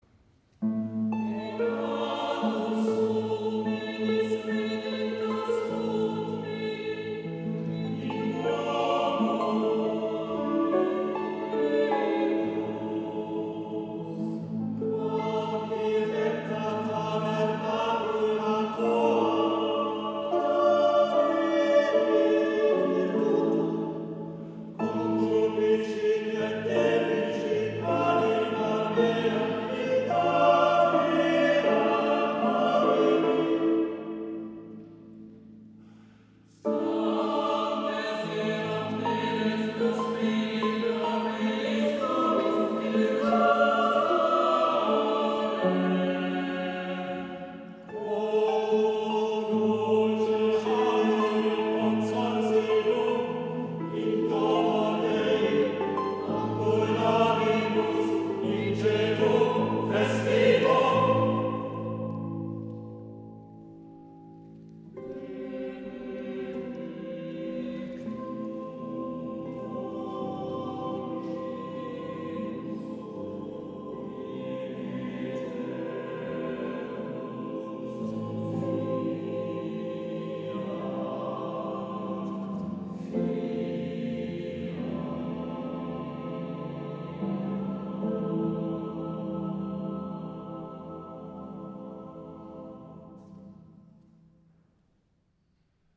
concert
Psalmenrequiem van Daan Manneke (harp